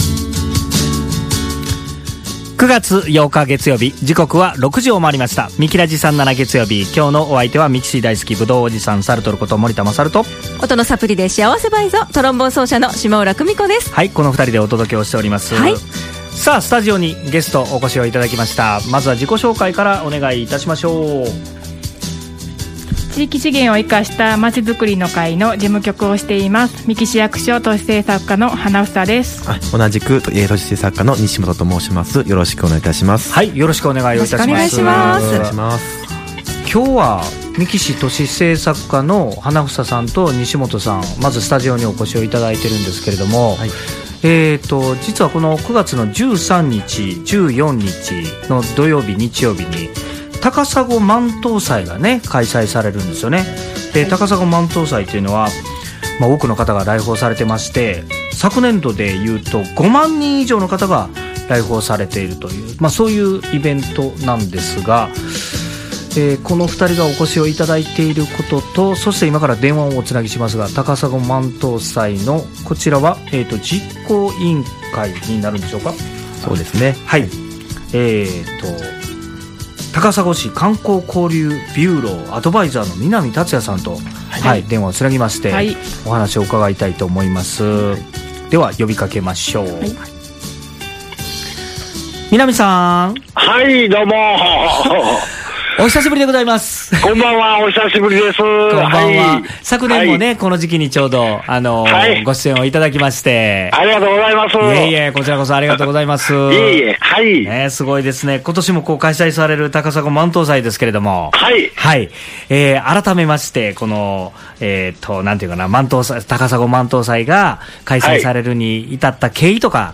電話とスタジオにて「たかさご万灯祭」のＰＲをしていただきました。